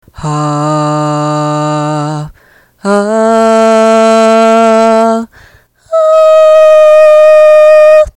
全体にＡｕｄａｃｉｔｙの機能「増幅」をかけました。
♪音声（音量が大きいので注意）
ひとつめは音量があがっただけですが、ふたつ目・三つ目はばりばりと振動するのがわかると思います。
これが音割れです。ざりざりして耳障りが良くないというか、痛いです。